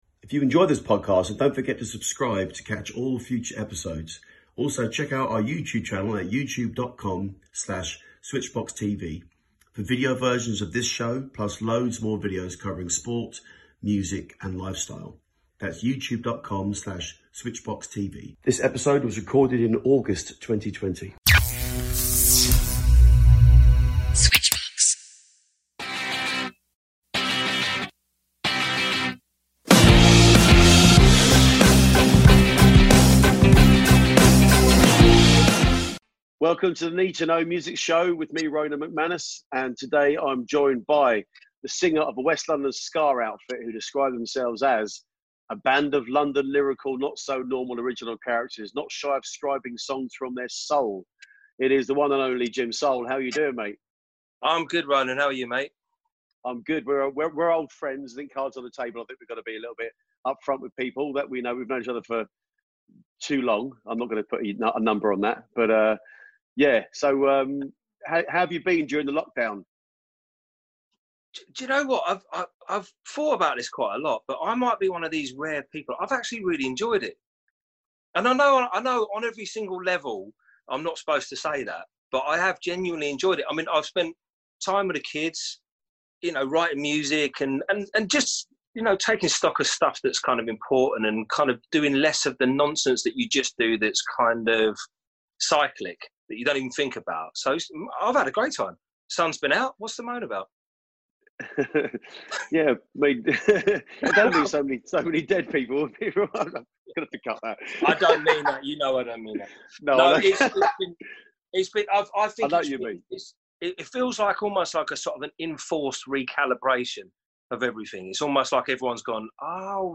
NEED2KNOW MUSIC SHOW